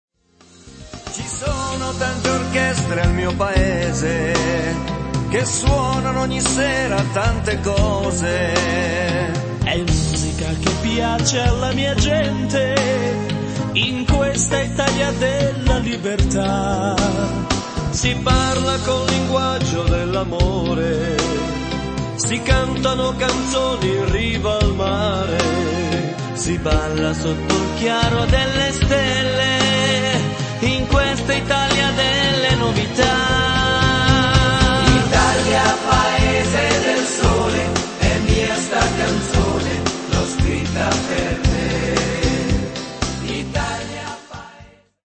beguine